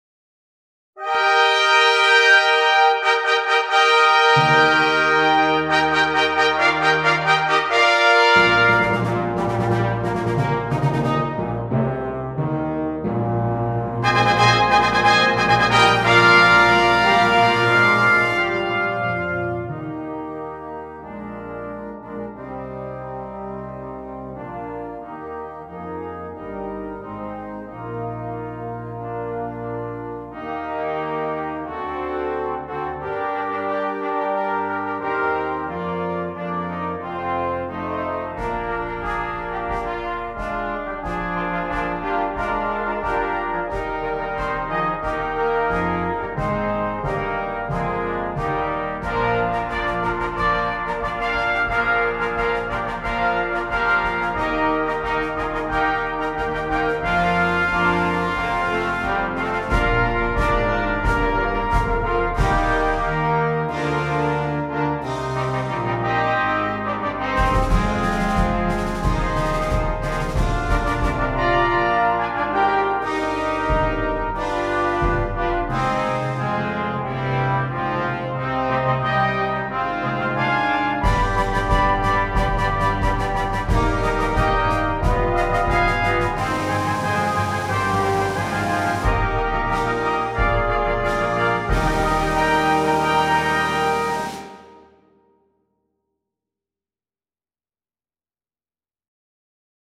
Brass Choir (4.2.2.1.1.perc)